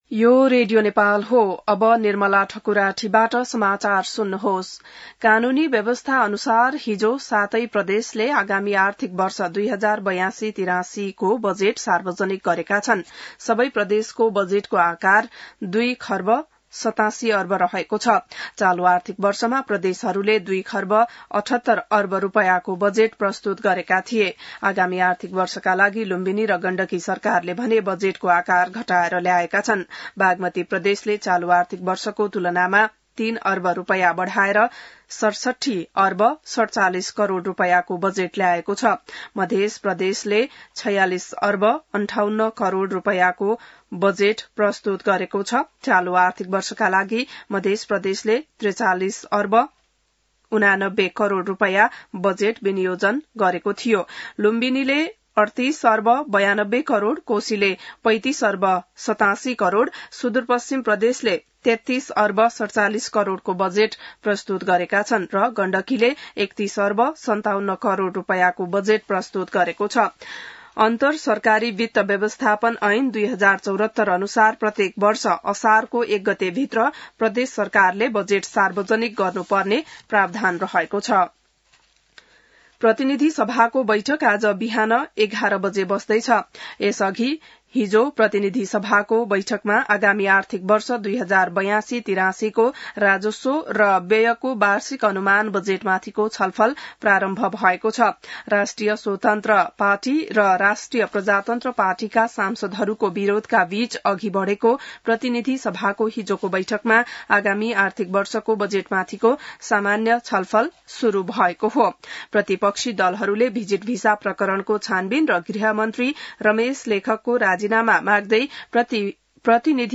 बिहान १० बजेको नेपाली समाचार : २ असार , २०८२